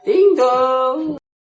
Best Ringtones, Korean Ringtones